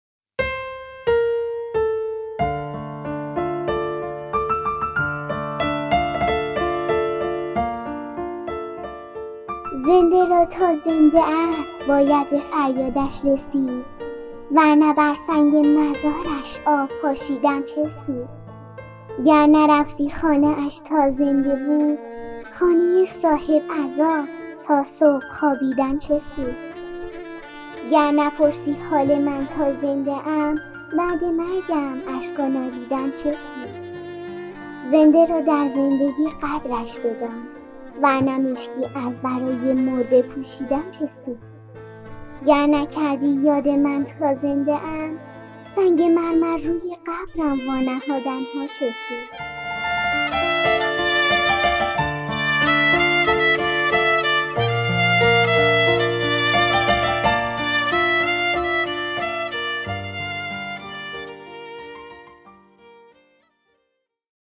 مادر - داستان صوتی کوتاه - تا زنده است باید به فریادش رسید - میقات مدیا